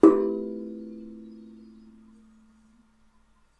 坦克鼓的声音 " TD G4手
描述：这声音是用我的钢舌鼓创造的。
标签： 汉克鼓 钢舌鼓 打击乐器 坦克鼓
声道立体声